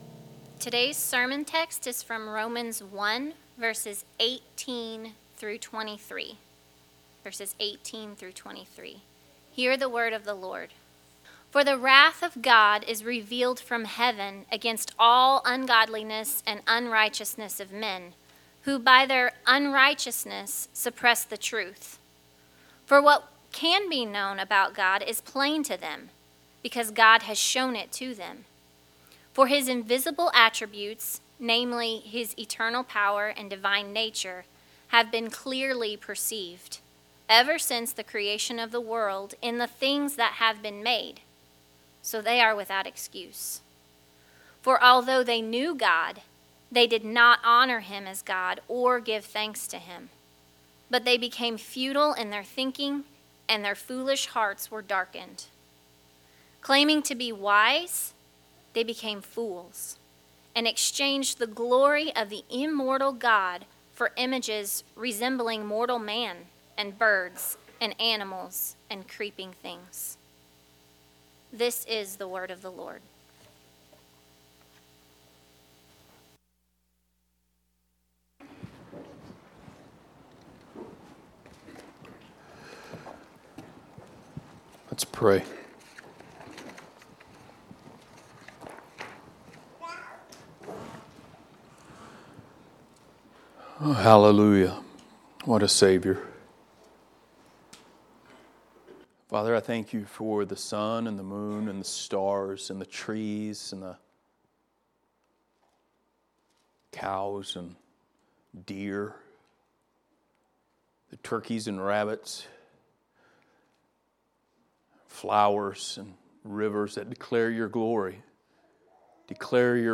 Passage: Romans 1:21-23 Service Type: Sunday Morning